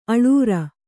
♪ aḷur